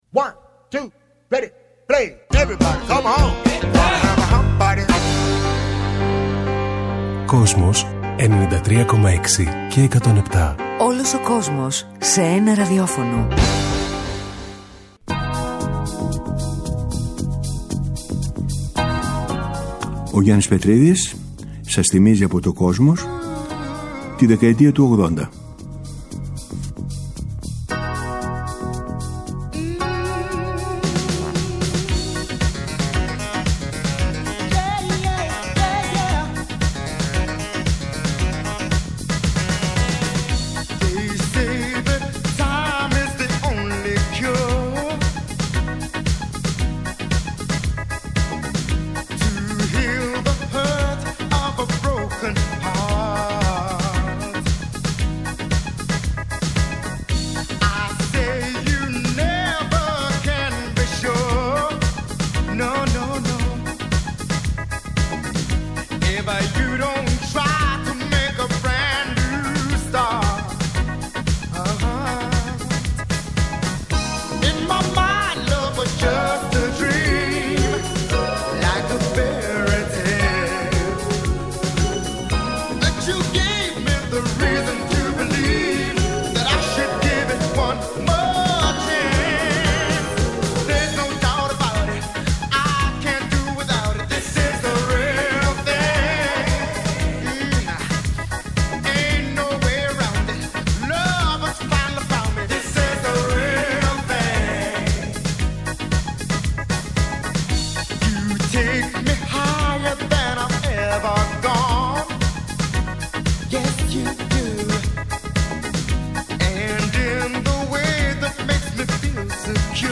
Μετά την ολοκλήρωση του αφιερώματος στη μουσική του 21ου αιώνα, ο Γιάννης Πετρίδης παρουσιάζει ένα νέο μουσικό αφιέρωμα στην 20ετία 1980-2000, από την Κυριακή 14 Μαρτίου 2021 και κάθε Κυριακή στις 19:00 στο Kosmos 93.6. Παρουσιάζονται, το ξεκίνημα της rap, η μεταμόρφωση του punk σε new wave, οι νεορομαντικοί μουσικοί στην Αγγλία, καθώς και οι γυναίκες της pop στην Αμερική που άλλαξαν τη δισκογραφία.